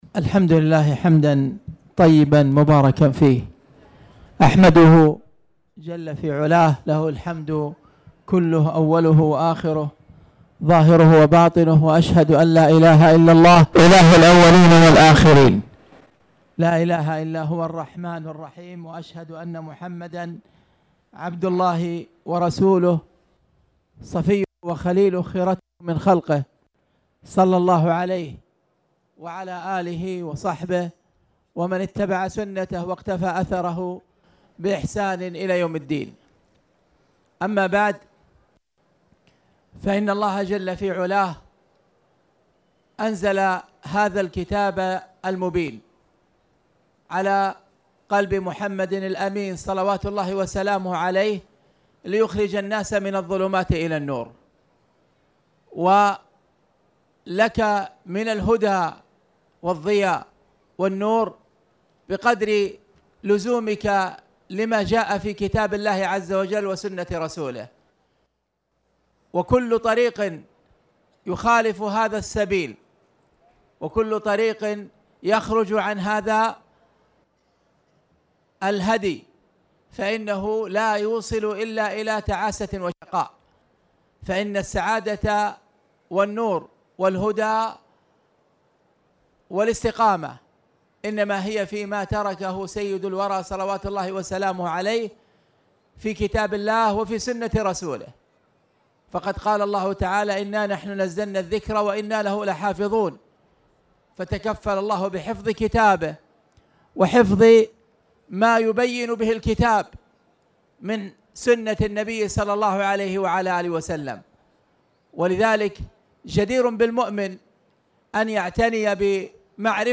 تاريخ النشر ١٤ صفر ١٤٣٨ هـ المكان: المسجد الحرام الشيخ: فضيلة الشيخ أ.د. خالد بن عبدالله المصلح فضيلة الشيخ أ.د. خالد بن عبدالله المصلح كتاب الصلاة-باب الأذان